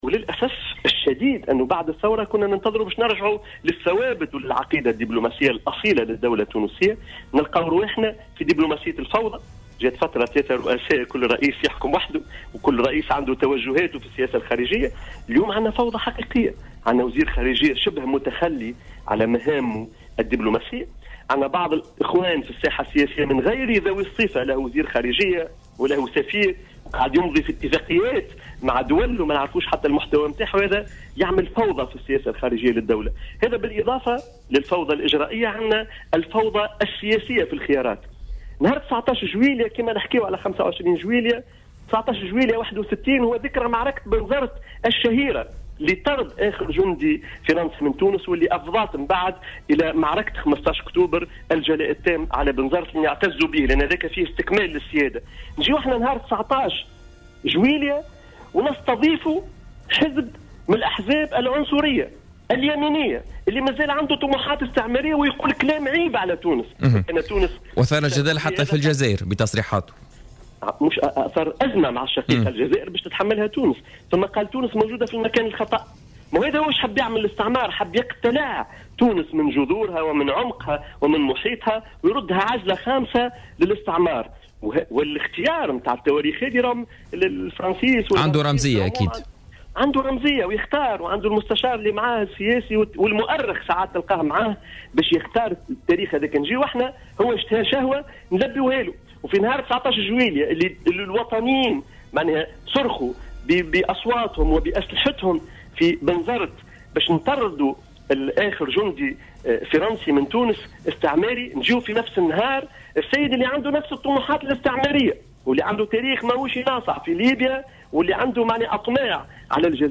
ضيف برنامج